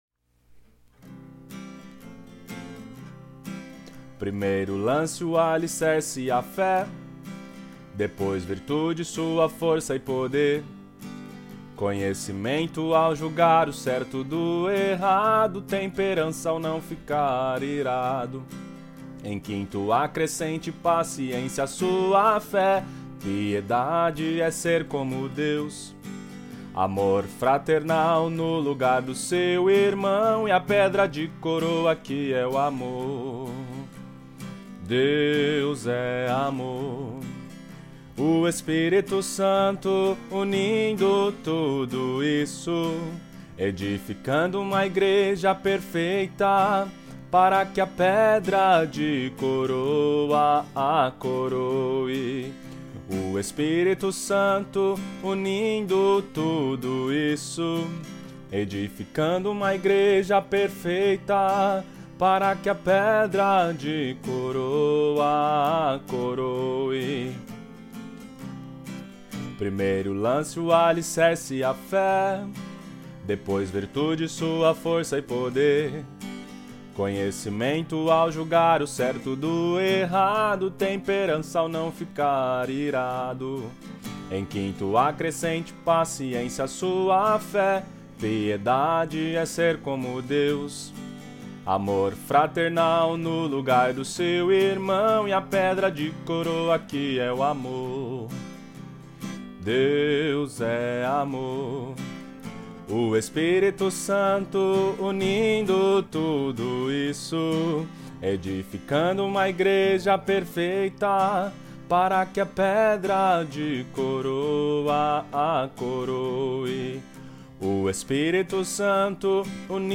Corinho Estatura